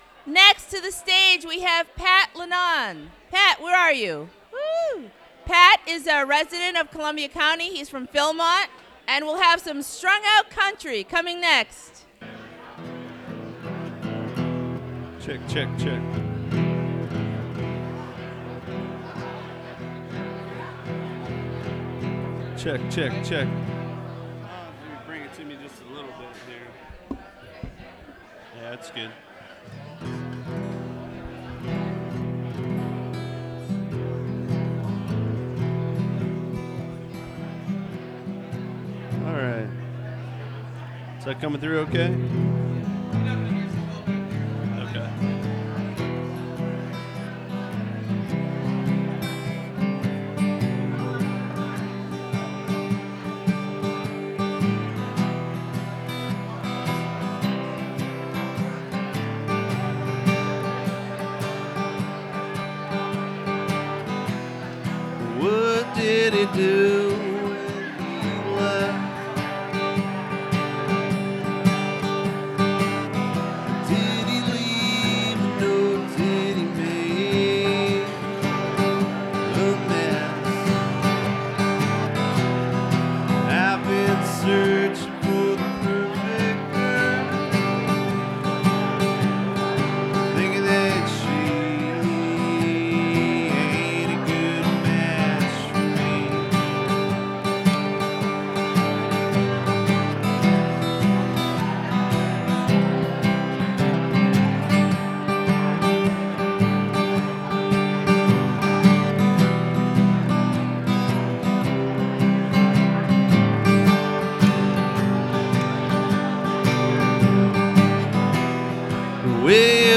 Recorded from WGXC 90.7-FM webstream.